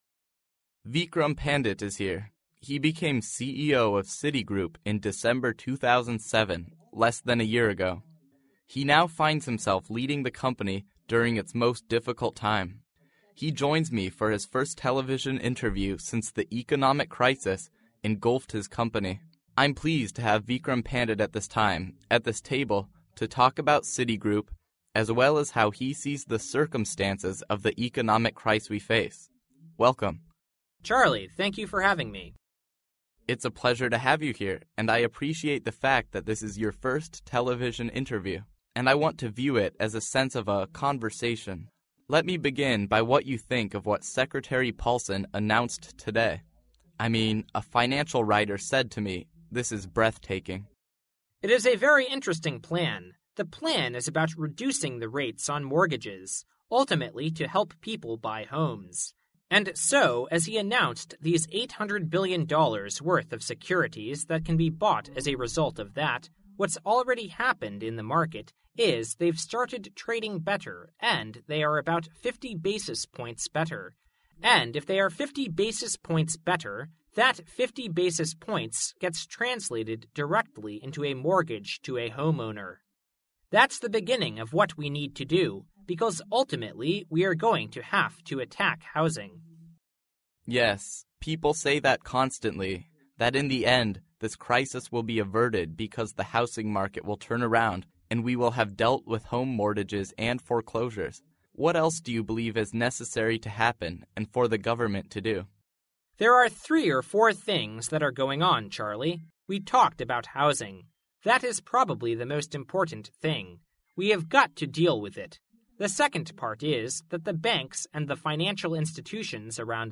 世界500强CEO访谈 第28期:花旗集团潘迪特 尽力确保增加股本总额(1) 听力文件下载—在线英语听力室